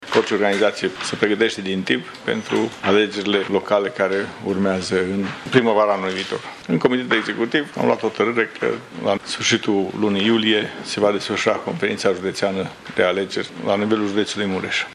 Președintele PSD Mureș, Vasile Gliga.